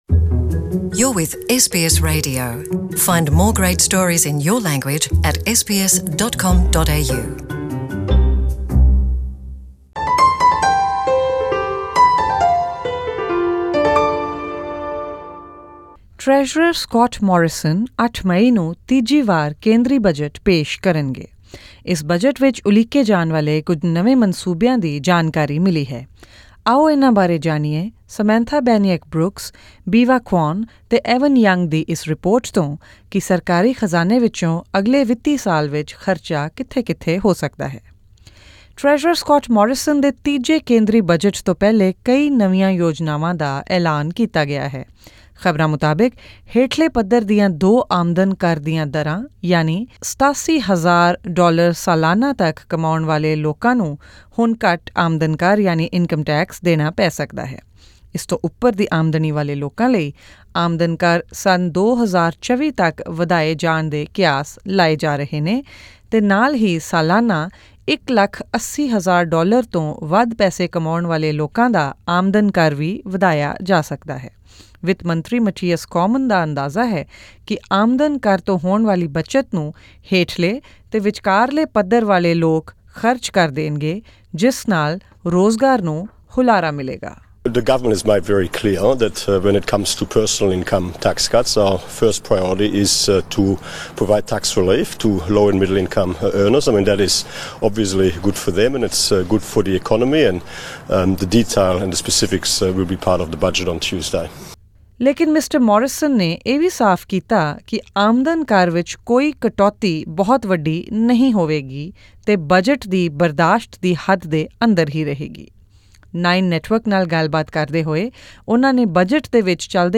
This report by takes a look at some of the places government funds might be headed in the next financial year.